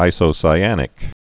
(ī-sō-sī-ănĭk)